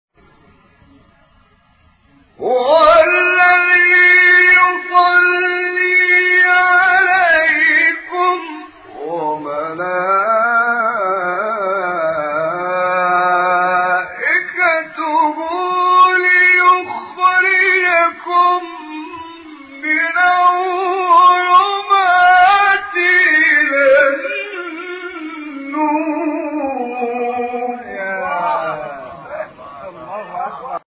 گروه شبکه اجتماعی: فرازهایی از تلاوت قاریان ممتاز مصری در زیر ارائه می‌شود.
فرازی از محمد عمران ترکیب مقام‌های حجاز، صبا، چهارگاه